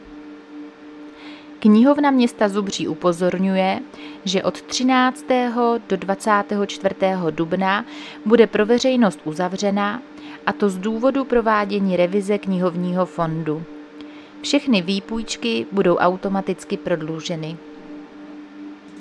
Záznam hlášení místního rozhlasu 9.4.2026
Zařazení: Rozhlas